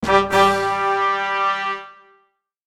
09569 trumpets ta da fanfare
brass complete fanfare game success ta-dam trumpets win sound effect free sound royalty free Gaming